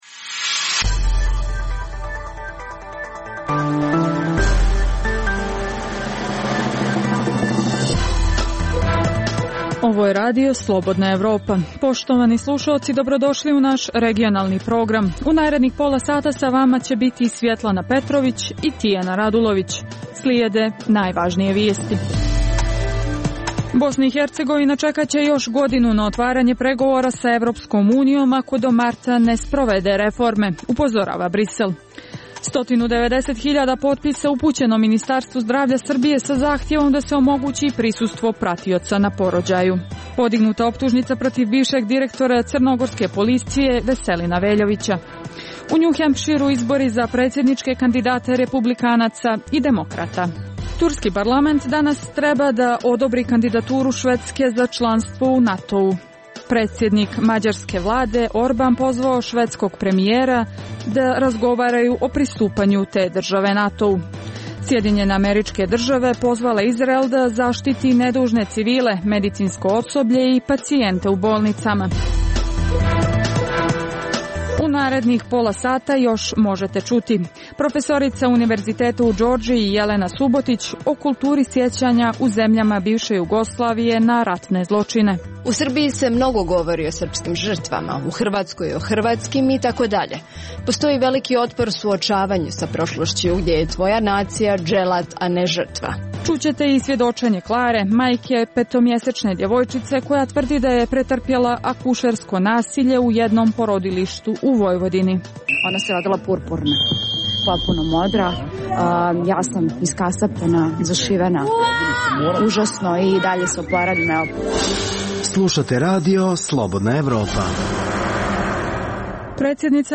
Emisija o dešavanjima u regionu (BiH, Srbija, Kosovo, Crna Gora, Hrvatska) i svijetu. Prvih pola sata emisije sadrži najaktuelnije i najzanimljivije priče o dešavanjima u zemljama regiona i u svijetu (politika, ekonomija i slično).
Reportaže iz svakodnevnog života ljudi su takođe sastavni dio “Dokumenata dana”.